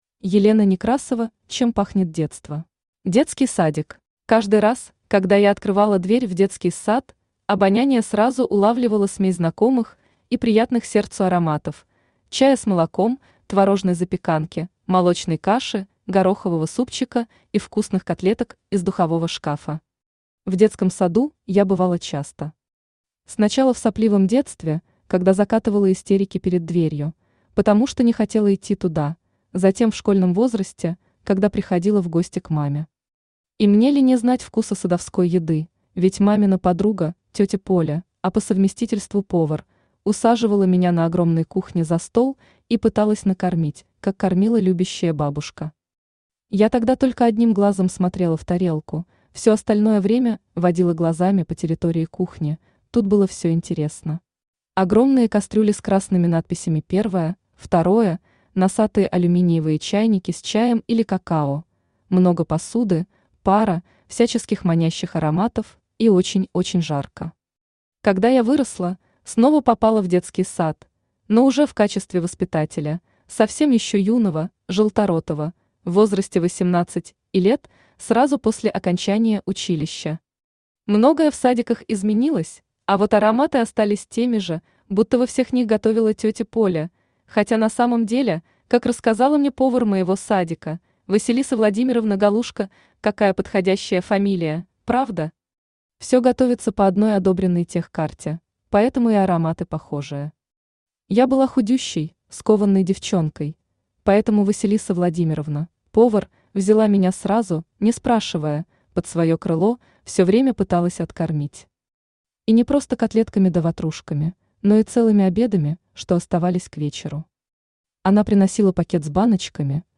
Аудиокнига Чем пахнет детство | Библиотека аудиокниг
Aудиокнига Чем пахнет детство Автор Елена Некрасова Читает аудиокнигу Авточтец ЛитРес.